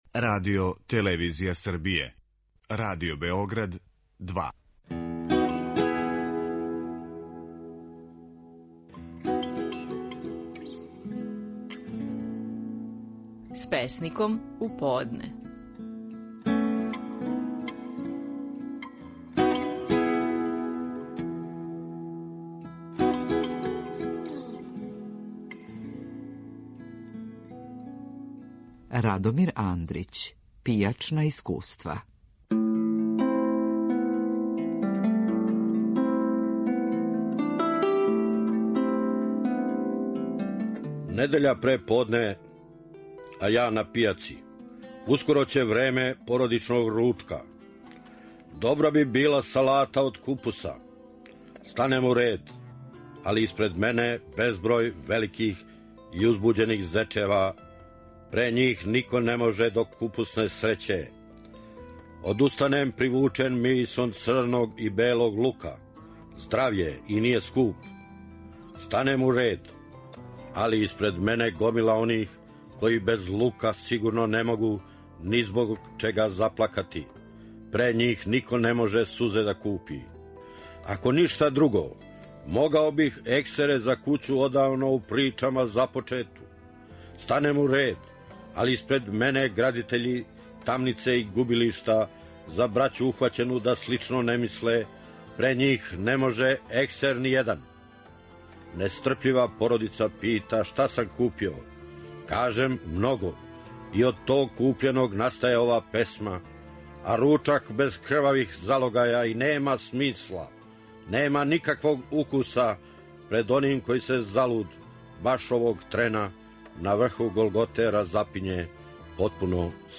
Стихови наших најпознатијих песника, у интерпретацији аутора.
Стихове песме „Пијачна искуства" казује Радомир Андрић.